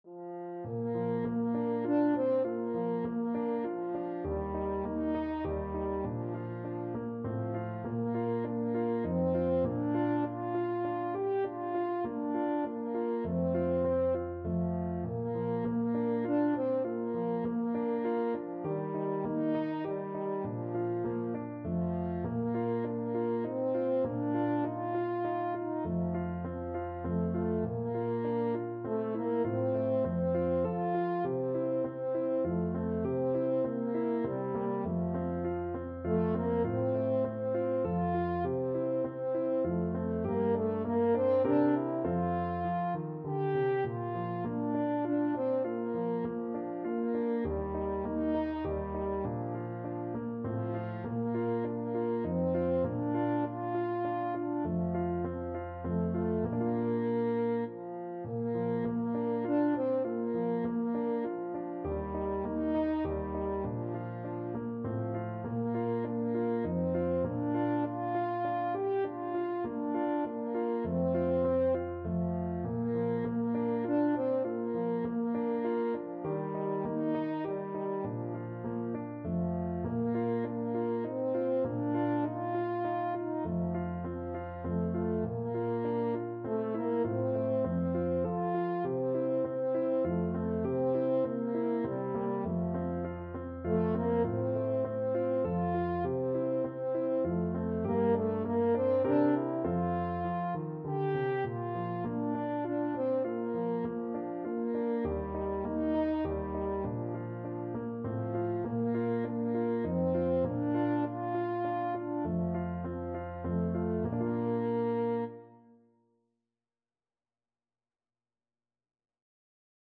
French Horn
Traditional Music of unknown author.
Bb major (Sounding Pitch) F major (French Horn in F) (View more Bb major Music for French Horn )
Moderato
Classical (View more Classical French Horn Music)